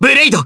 Fluss-Vox_Skill1-3_jp.wav